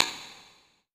Snr Rimverb.wav